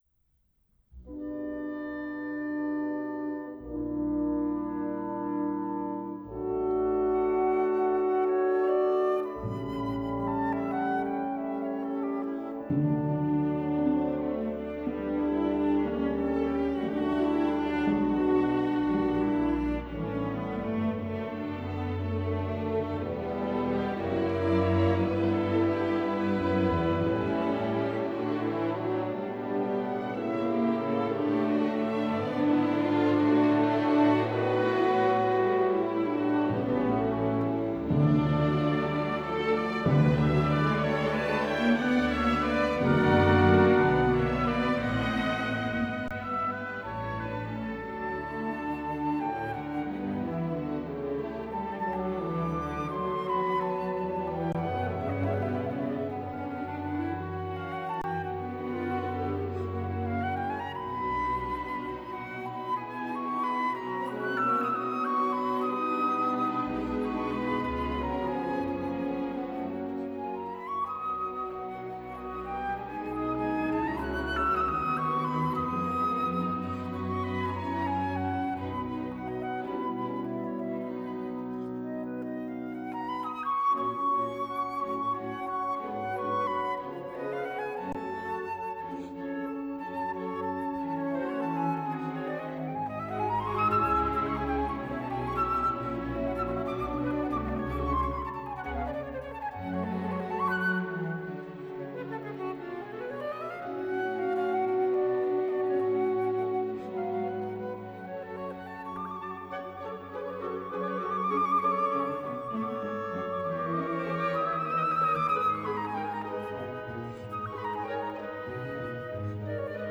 flute
Recorded at Odense Koncerthus, Denmark